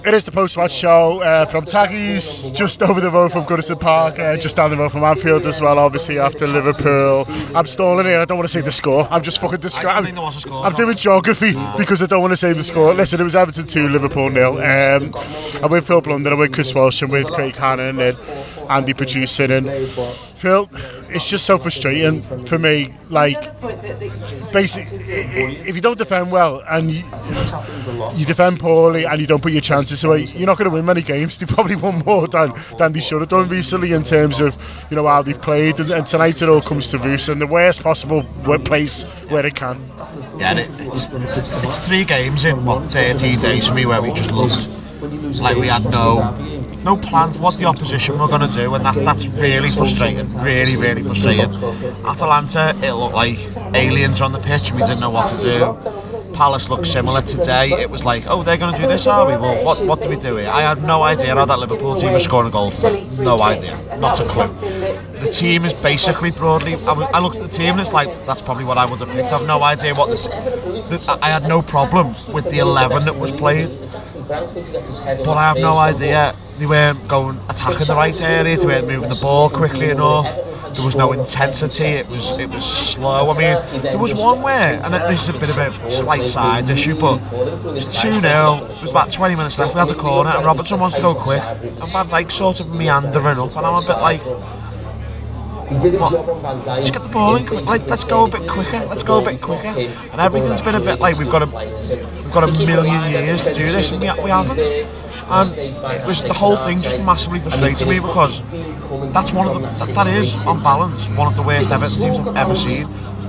Everton 2 Liverpool 0: Post-Match Show // Reaction Podcast